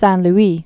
(săɴ-l-ē)